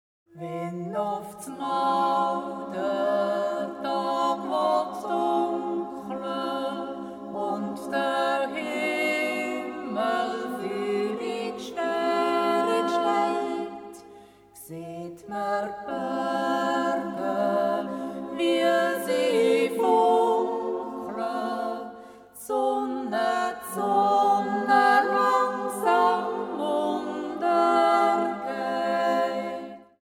Folk music; yodel; yodelling song
Women's yodel choir